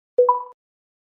Звук полученного письма на электронный ящик, мелодии сообщений и уведомлений в mp3
2. Звук уведомления о входящем письме на электронный ящик